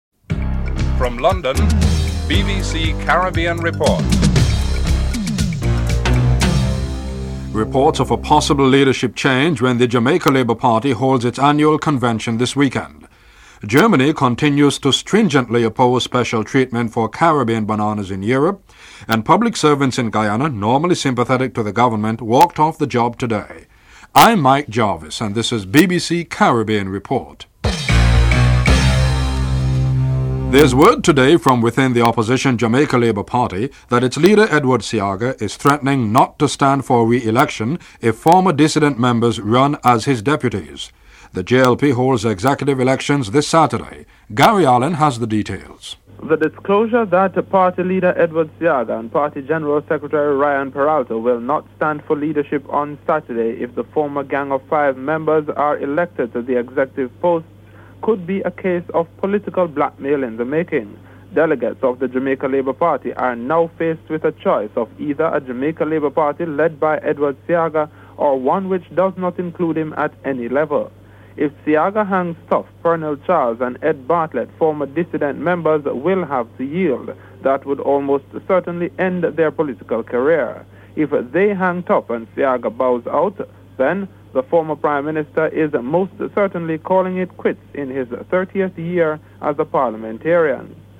Report ends abruptly
1. Headlines (00:00-00:29)